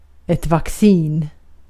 Uttal
Uttal Okänd accent: IPA: /vakˈsiːn/ Ordet hittades på dessa språk: svenska Översättning 1. aşı Artikel: ett .